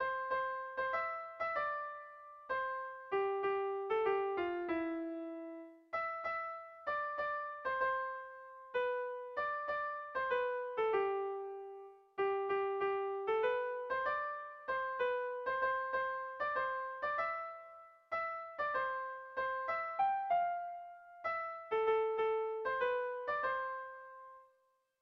Dantzakoa
Zortziko txikia (hg) / Lau puntuko txikia (ip)
ABDE